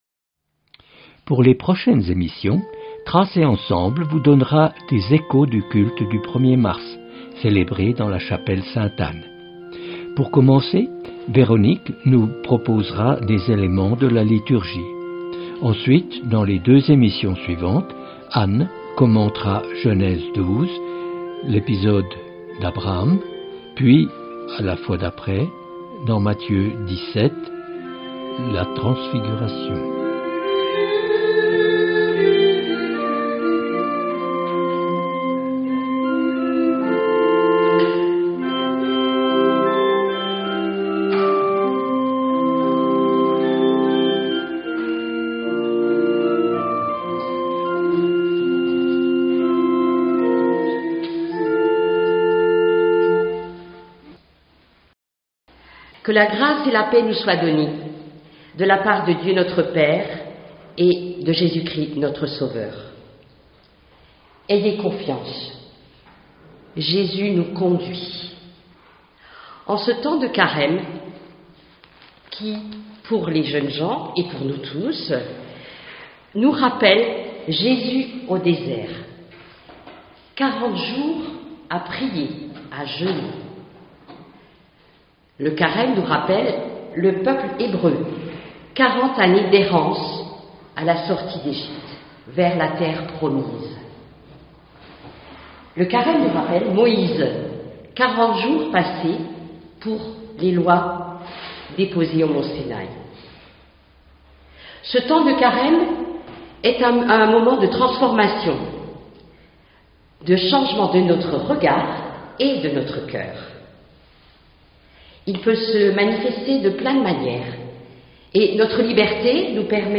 Début du culte du 1er mars